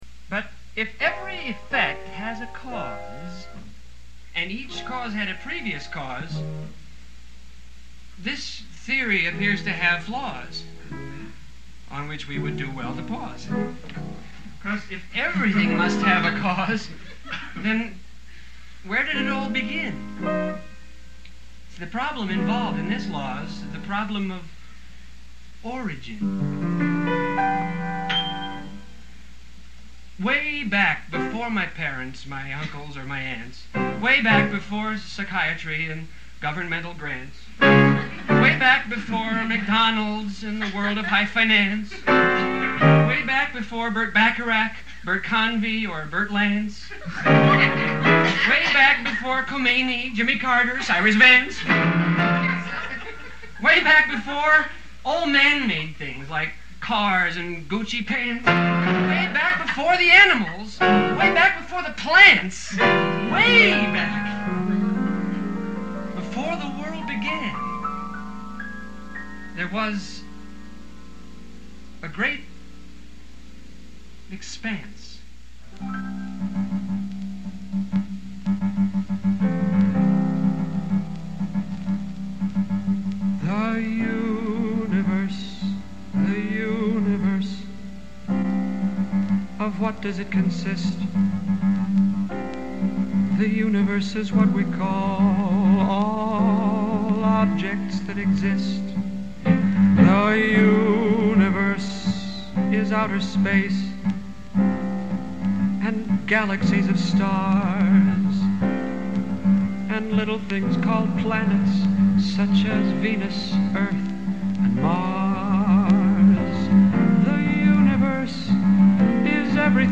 ” is a rhymed pianolog that segues into the next song